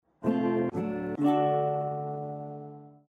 SnootGame/game/audio/effects/correctA.ogg at 7790d9c8a823f6a547be9bd410296cc5889a5ca1
correctA.ogg